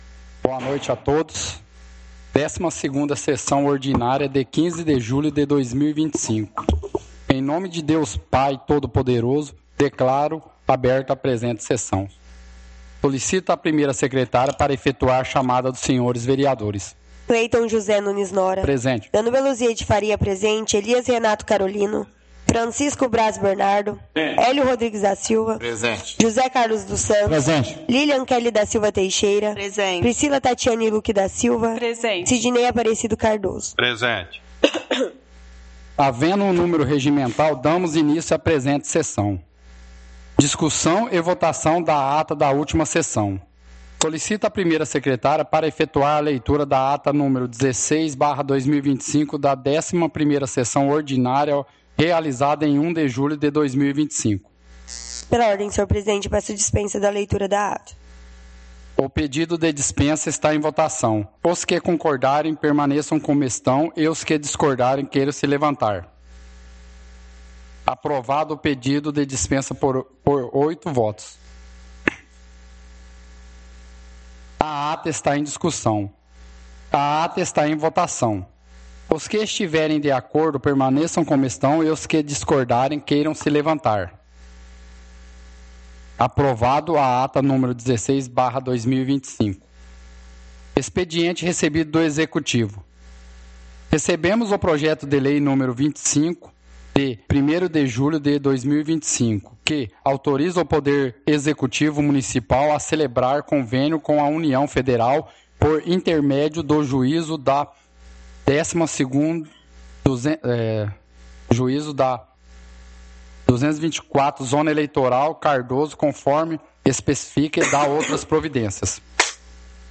Áudio da 12ª Sessão Ordinária – 15/07/2025